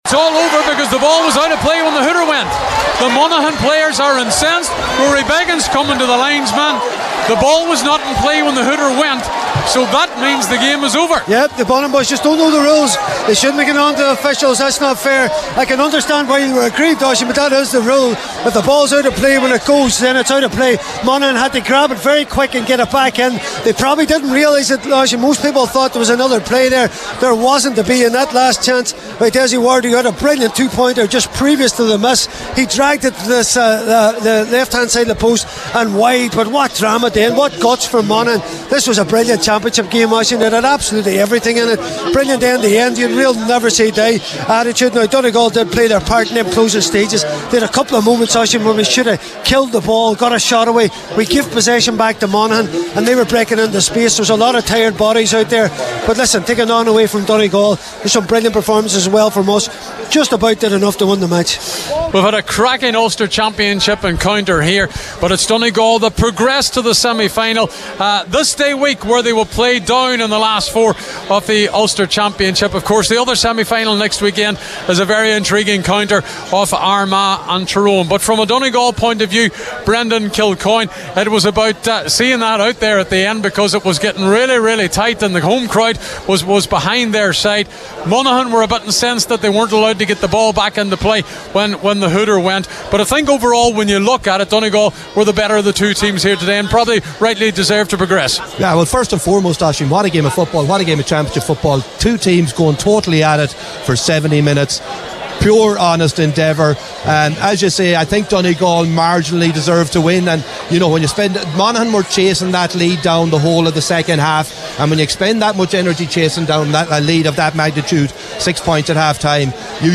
Post-Match Reaction